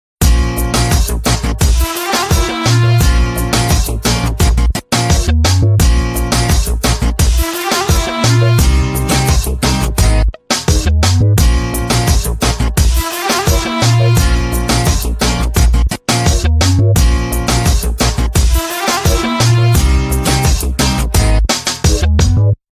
Rock Ringtones